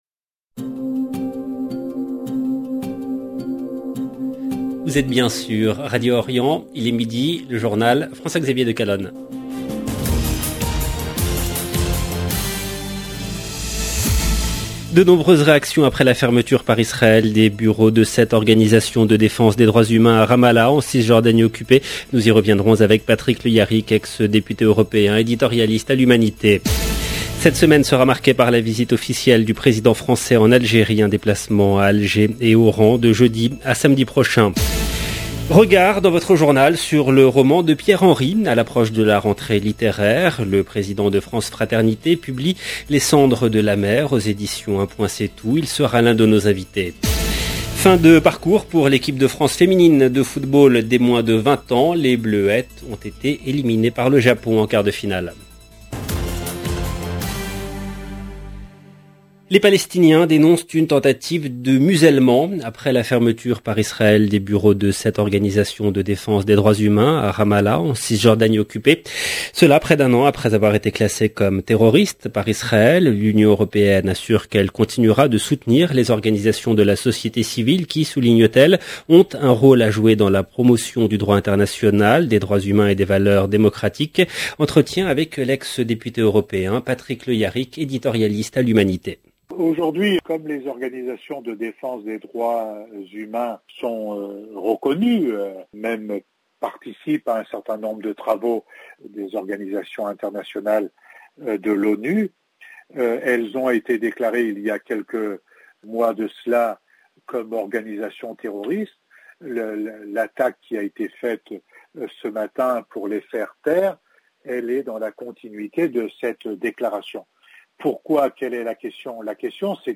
LE JOURNAL DE 12H EN LANGUE FRANCAISE DU 22/8/2022
17 min 54 sec LE JOURNAL DE 12H EN LANGUE FRANCAISE DU 22/8/2022 JS EDITION DU JOURNAL DE 12 H EN LANGUE FRANCAISE DU 22/8/2022 De nombreuses réactions après la fermeture par Israël des bureaux de sept organisations de défense des droits humains à Ramallah en Cisjordanie occupée. Nous y reviendrons avec Patrick Le Hyaric, ex député européen, éditorialiste à l’Humanité. Cette semaine sera marquée par la visite officielle du président français en Algérie.